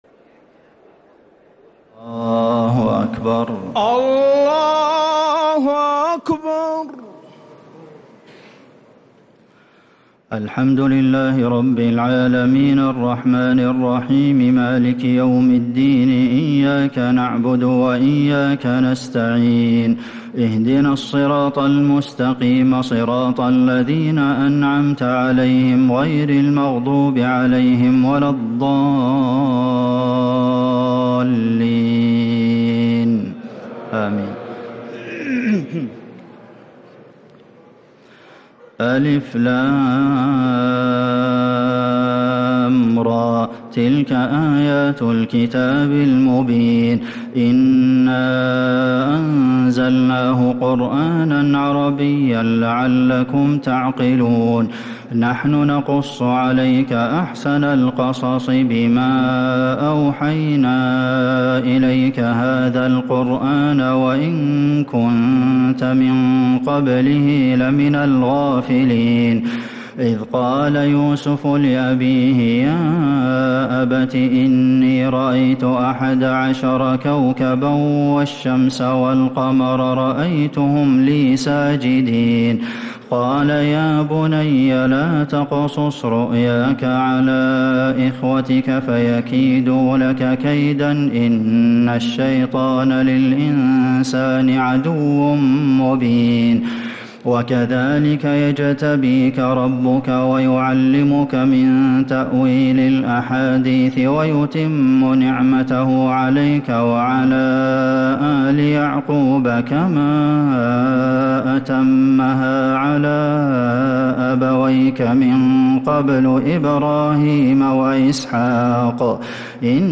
تراويح ليلة 16 رمضان 1446هـ من سورة يوسف {1-57} Taraweeh 16th night Ramadan 1446H Surah Yusuf > تراويح الحرم النبوي عام 1446 🕌 > التراويح - تلاوات الحرمين